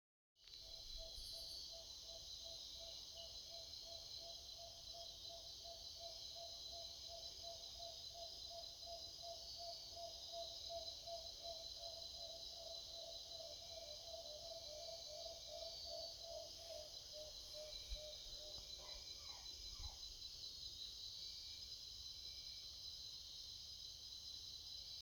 Tawny-bellied Screech Owl (Megascops watsonii)
Life Stage: Adult
Province / Department: Madre de Dios
Location or protected area: Amazonia Lodge
Condition: Wild
Certainty: Recorded vocal